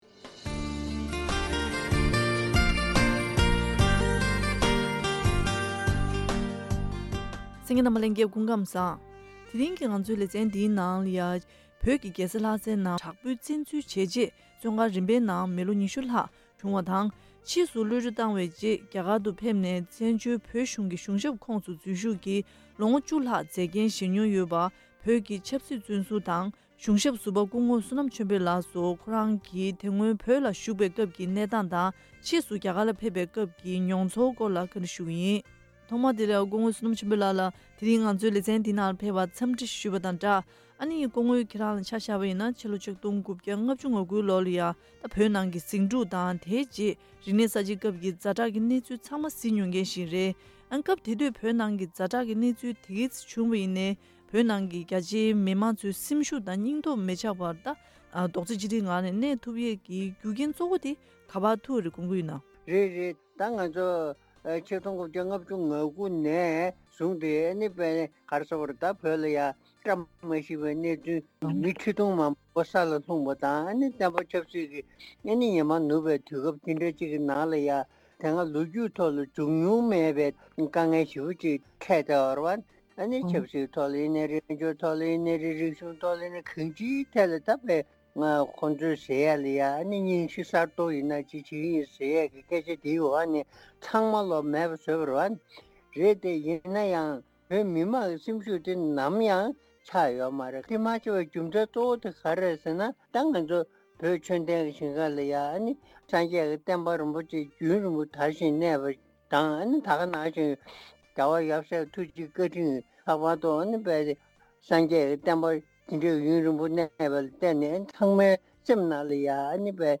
བཀའ་དྲི་ཞུས་པ་ཞིག་གསན་རོགས་གནང་།